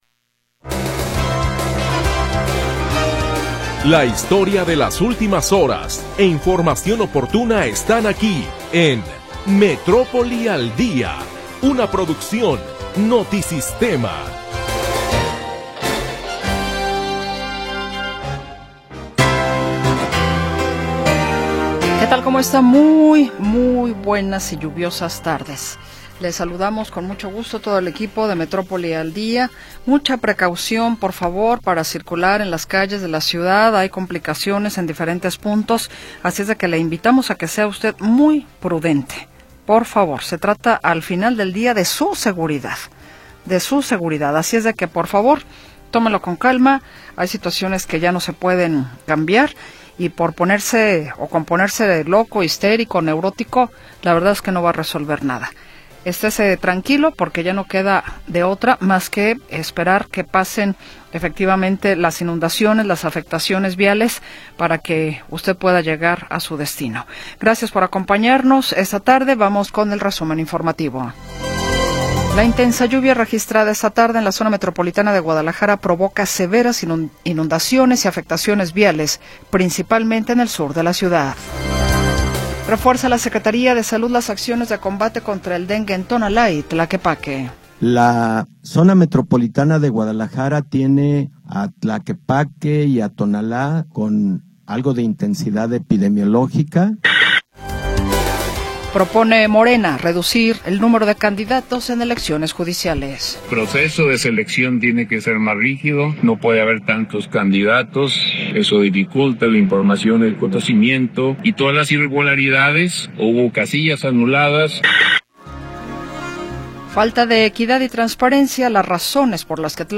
Primera hora del programa transmitido el 24 de Junio de 2025.